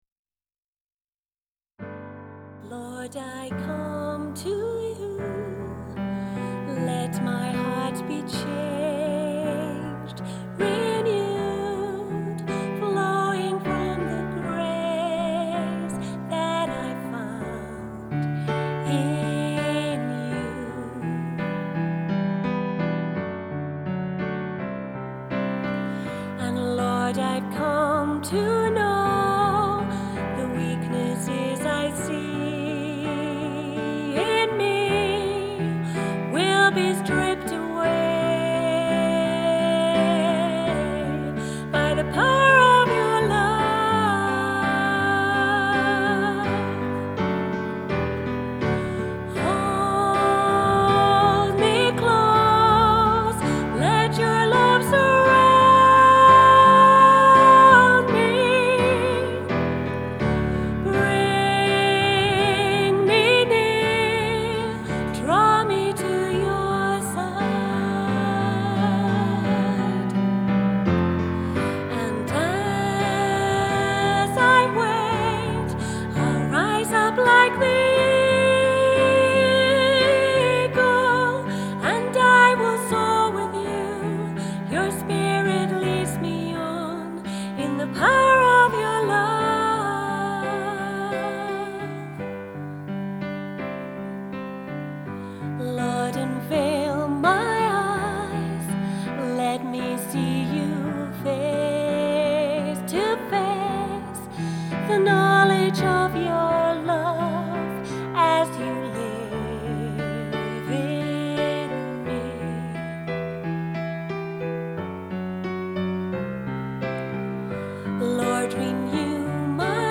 Praise Band At Home
Whilst we are unable to be together to play, the praise band and friends have been recording for the message each week.